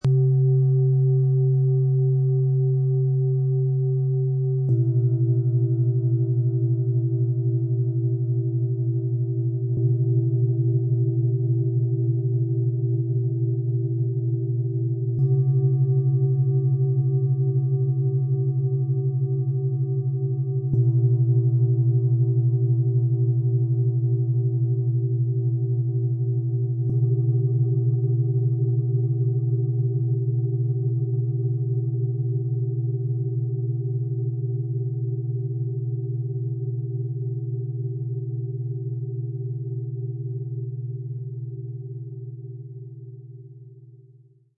Lebensenergie spüren, Vertrauen vertiefen und Raum für Wandel öffnen - Klangmassage & Klangpädagogik Set für Kinder und Jugendliche aus 3 Planetenschalen, Ø 19,3 -20,8 cm, 2,9 kg
Ein weicher Ton, der Emotionen ausbalanciert und den Zugang zu den eigenen Gefühlen erleichtert.
Mit dem Sound-Player - Jetzt reinhören lässt sich der Originalton der Schalen direkt anhören. Die Klänge sind sanft, klar und harmonisch - angenehm für Kinderohren, aber auch in der Klangmassage mit Erwachsenen wohltuend erlebbar.
Tiefster Ton: Biorhythmus Körper, Mond, Wasser
Bengalen Schale, Glänzend
Mittlerer Ton: Mond
Höchster Ton: Hopi-Herzton, Mond